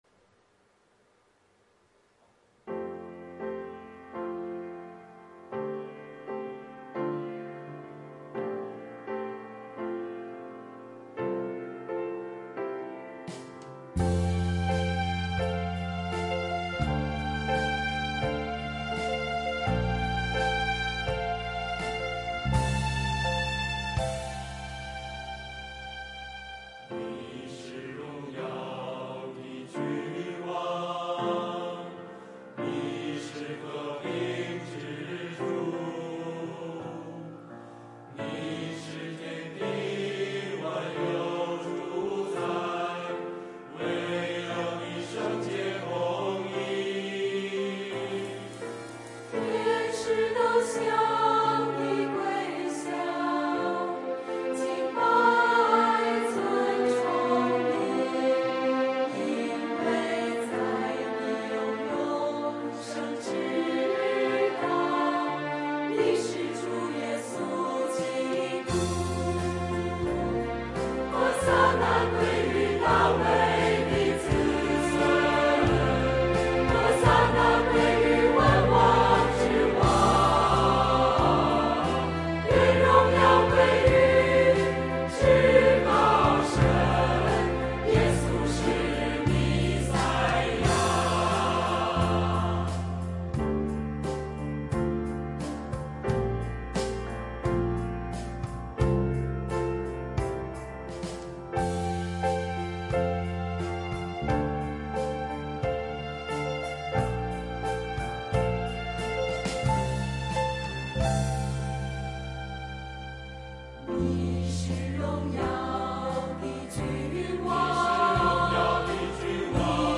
[2023年4月2日主日献唱]《祢是荣耀君王》 | 北京基督教会海淀堂